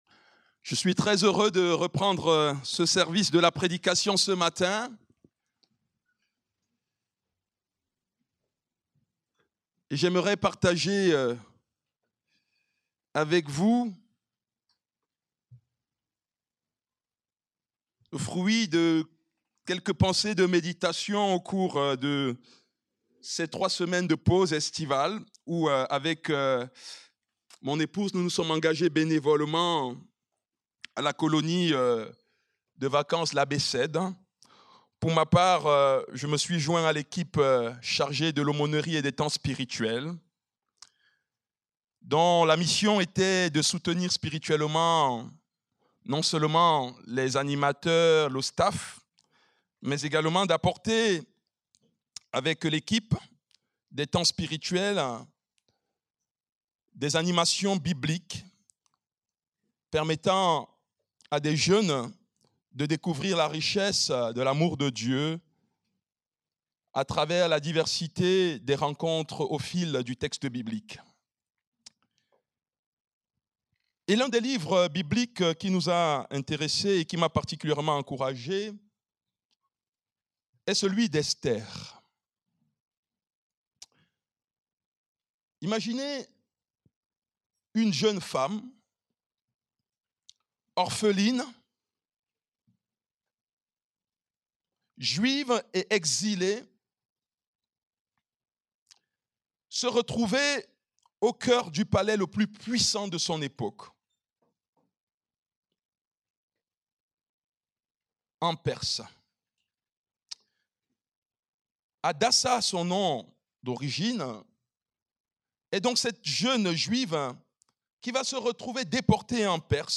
Culte du dimanche 10 août 2025, prédication